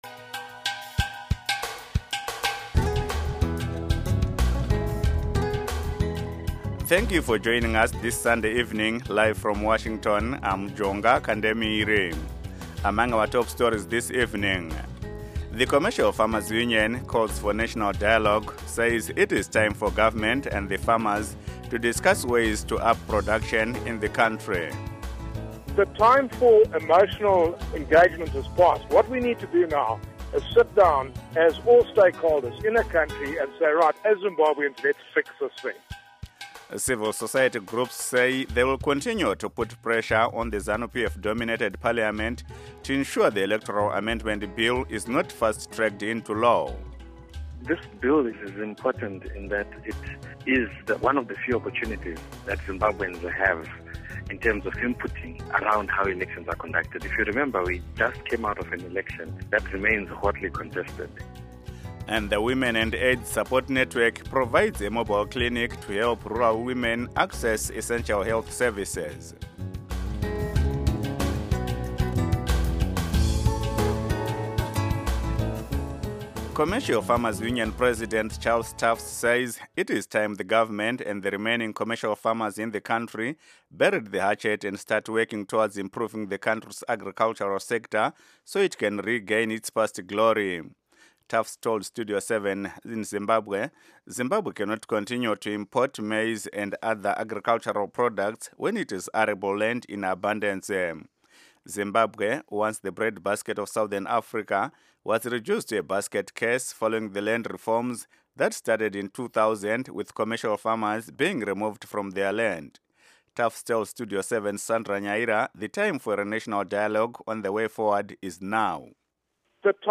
radio news